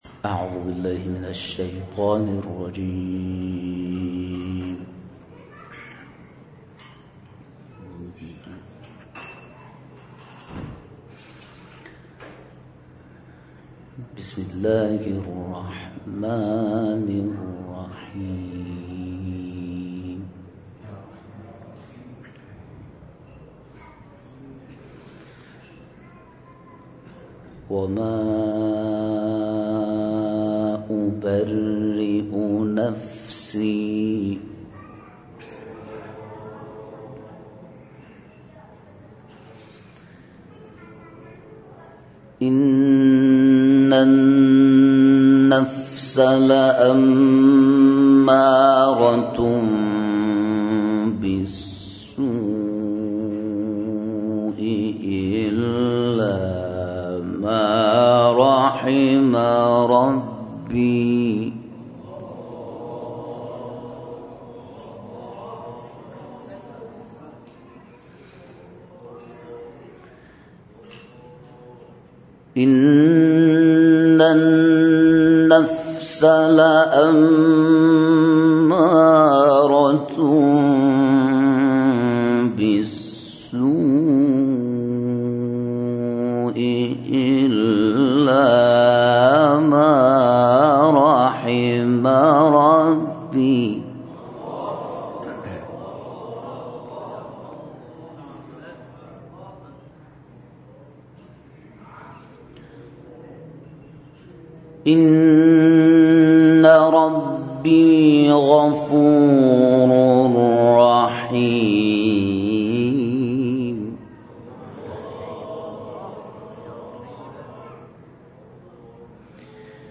جدیدترین تلاوت
در مسجد حضرت معصومه(ع)